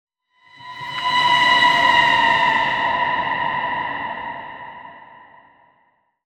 以撒发怒.wav